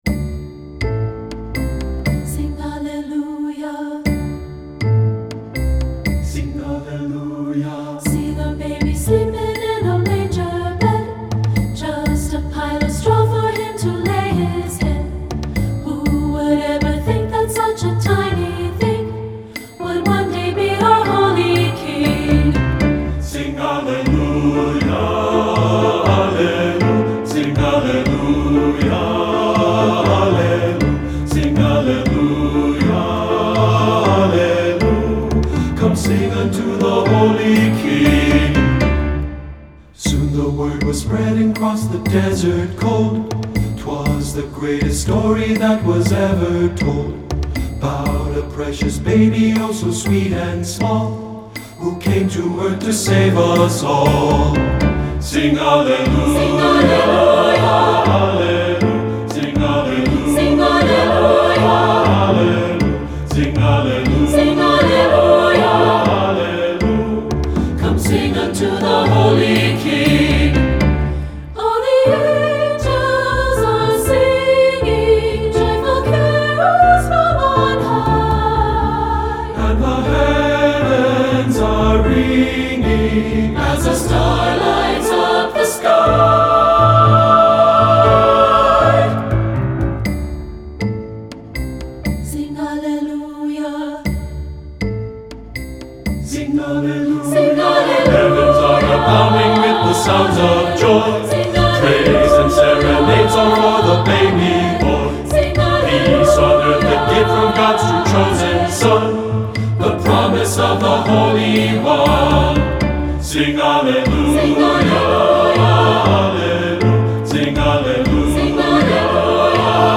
Voicing: SA(T)B and Piano